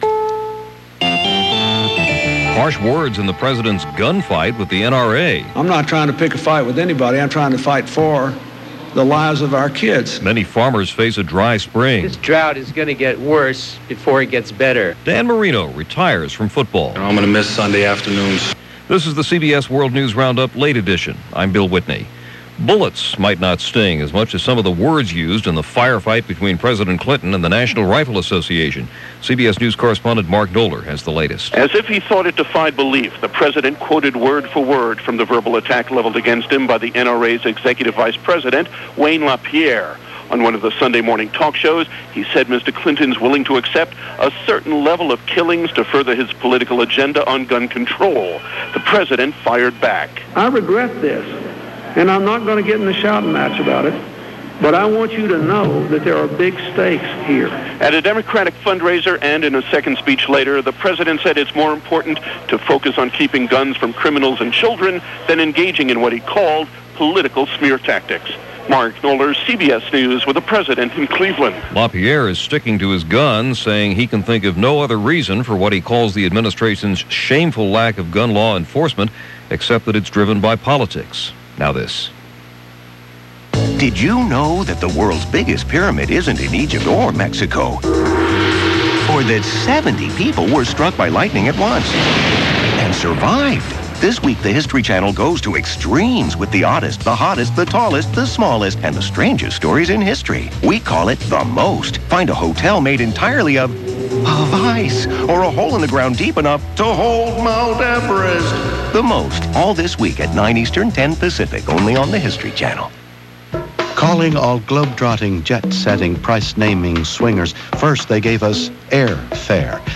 That’s just a small slice of what went on, this March 13th 2000, as reported by The CBS World News Roundup: Late Edition.